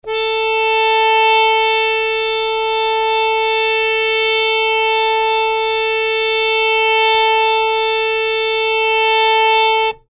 harmonium